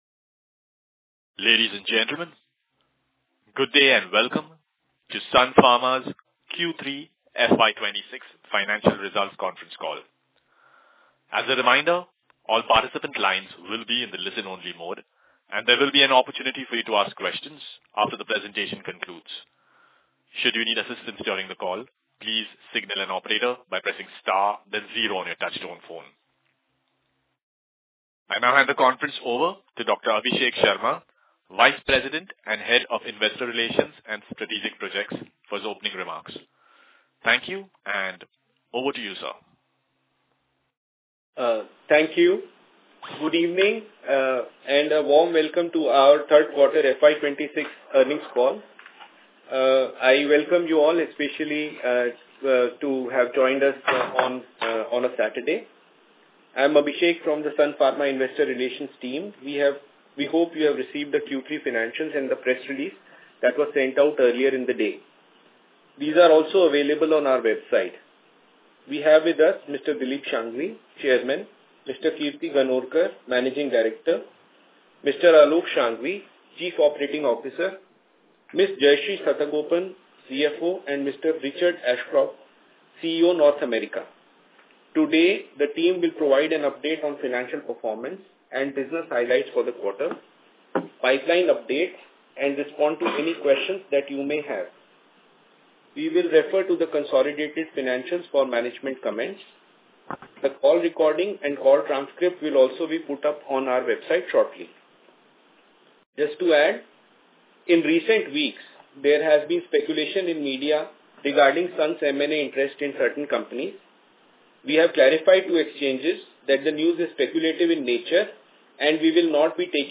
Investor Call Audio Recording